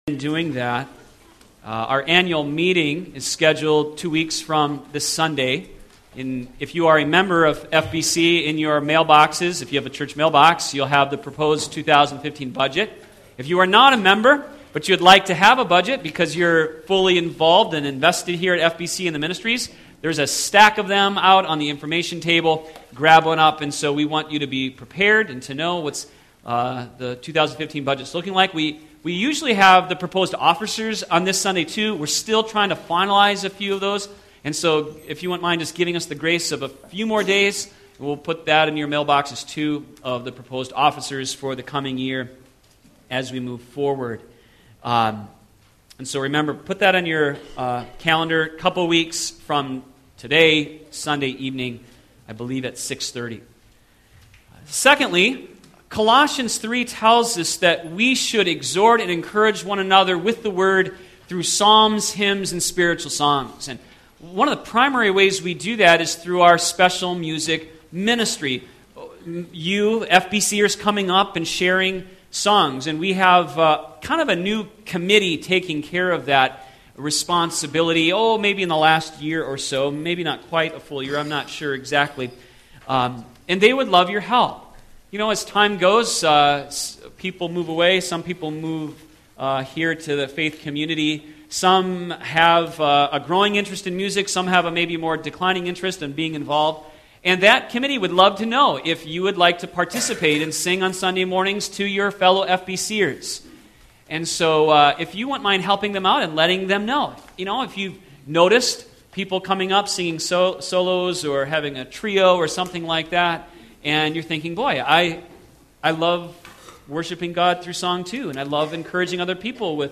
sermon11114.mp3